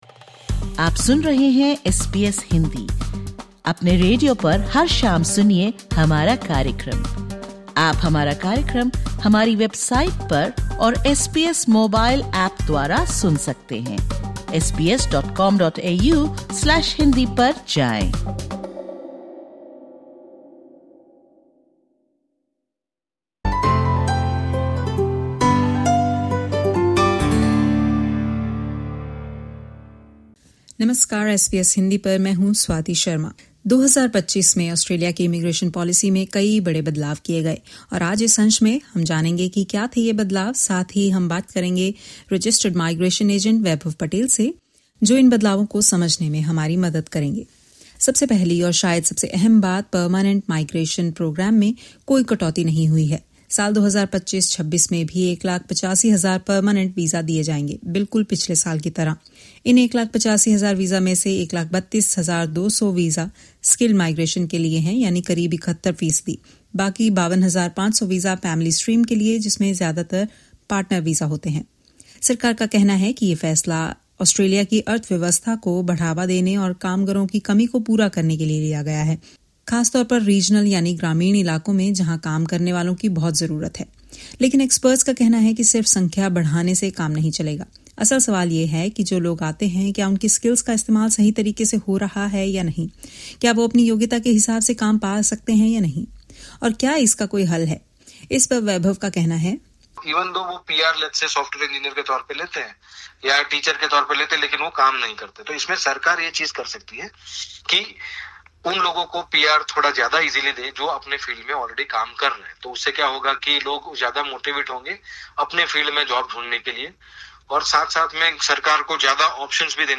Australia’s 2026 migration plan introduces major changes, with the permanent migration intake set at 185,000 visas and international student visa numbers increasing to 295,000. The government is also implementing stricter student visa screening for applicants from India, Nepal, Bhutan, and Bangladesh, citing a rise in fraudulent visa applications. In conversation with registered migration agent